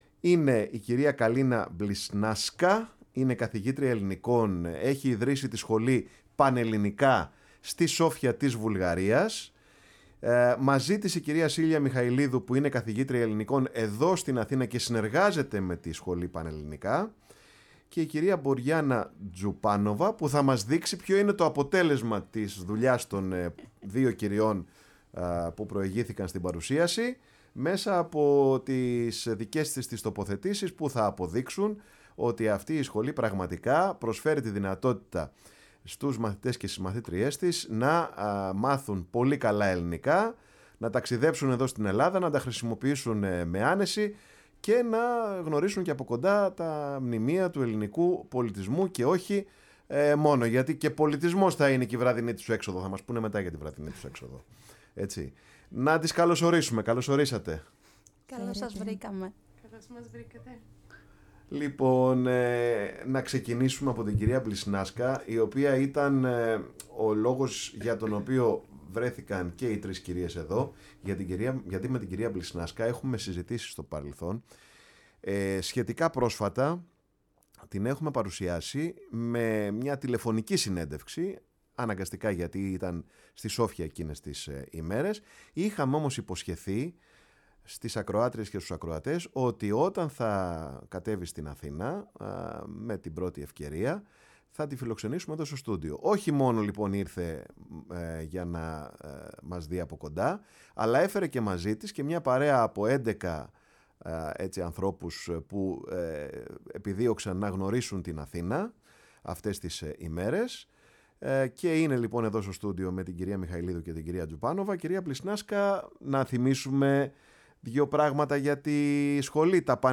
φιλοξένησε σήμερα στο στούντιο η εκπομπή ”Πάρε τον Χρόνο σου”